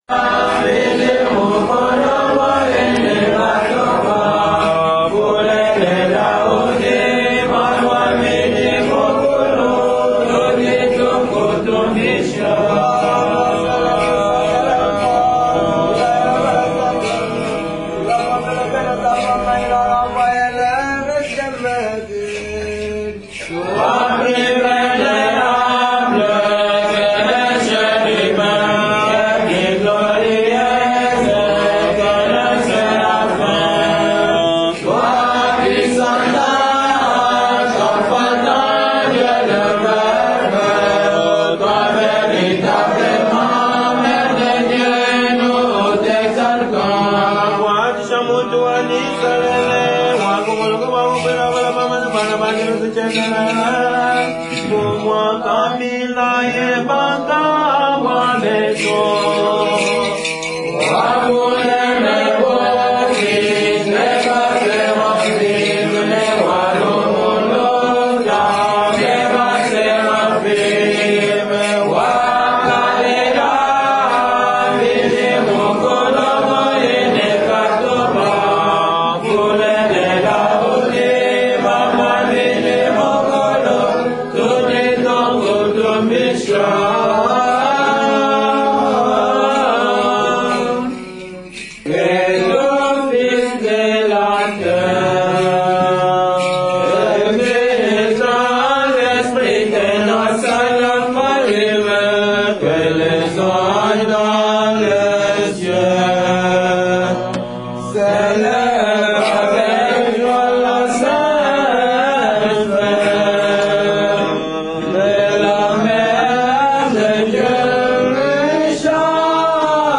We had a small audio recorder with us, so we tried to bring you some music from our church choirs in Africa. The sound quality is not the greatest, but we hope you like it none-the-less.